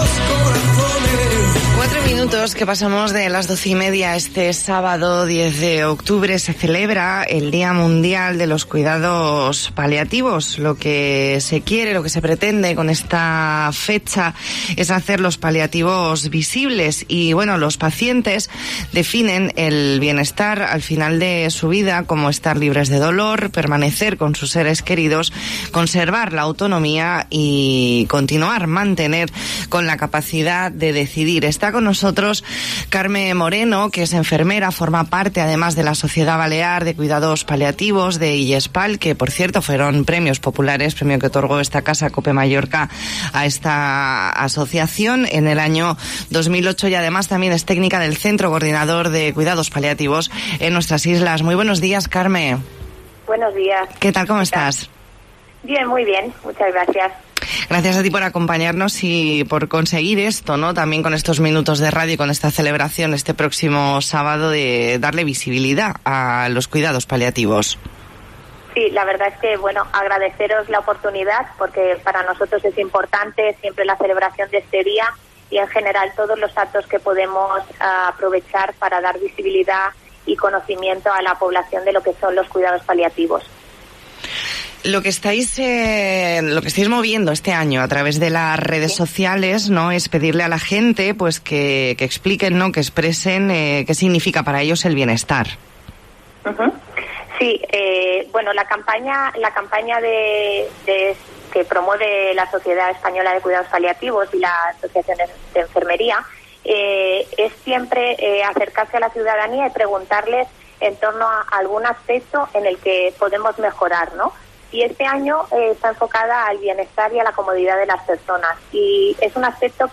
Entrevista en La Mañana en COPE Más Mallorca, jueves 8 de octubre de 2020.